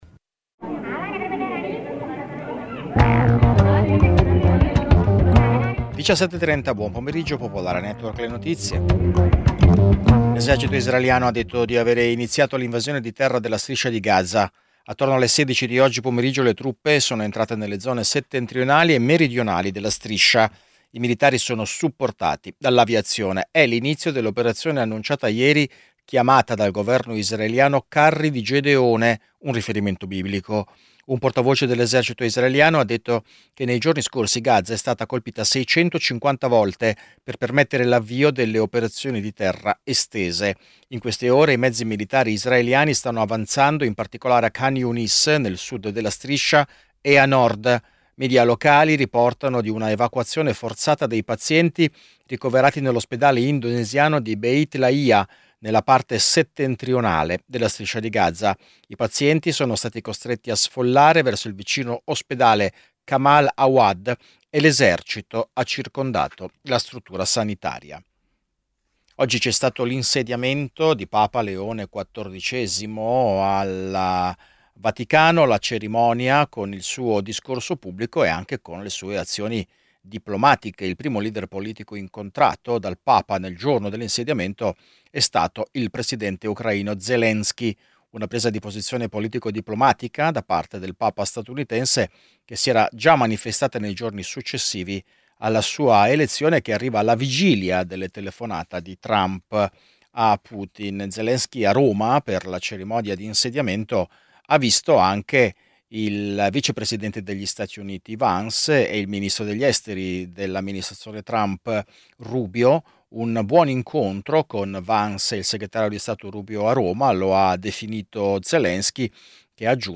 Giornale radio nazionale - del 18/05/2025 ore 17:30